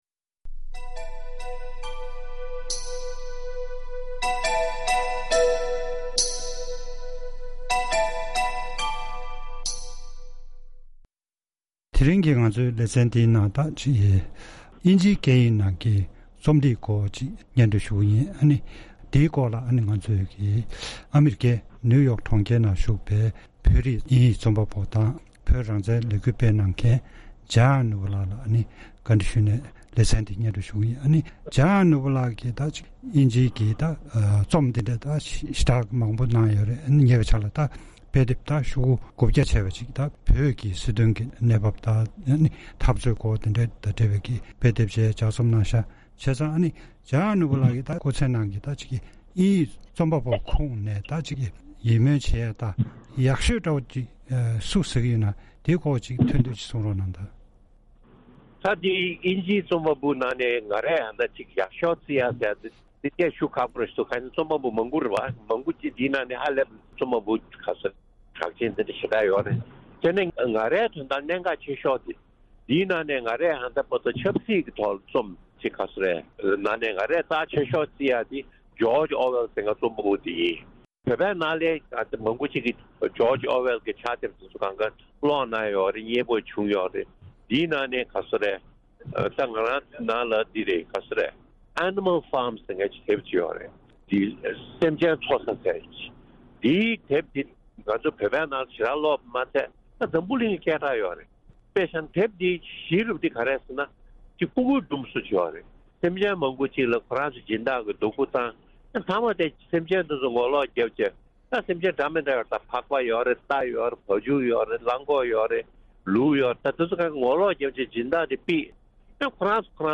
གནས་འདྲི་ཞུས་པ་ཞིག་གཤམ་ལ་གསན་རོགས་གནང་།